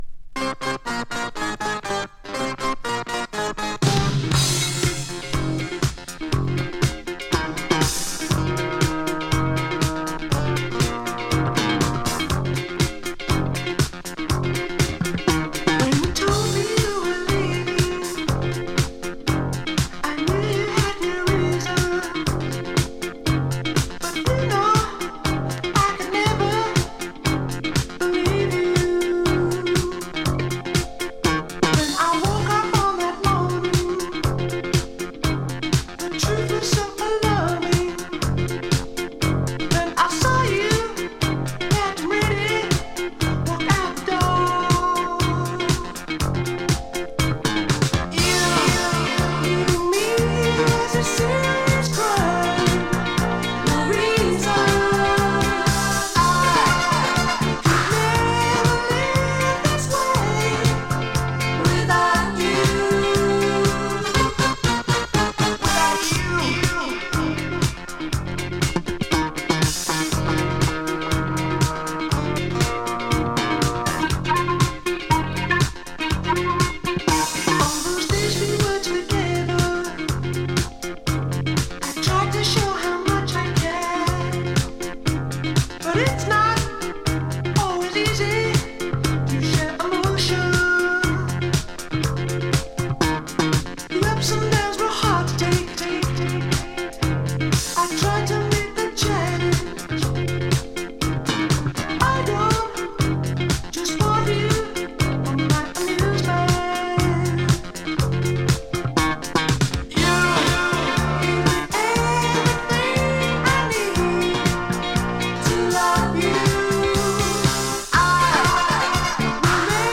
【NEW WAVE】 【UK】